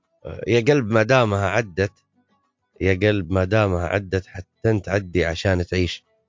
10_radio_KSA.mp3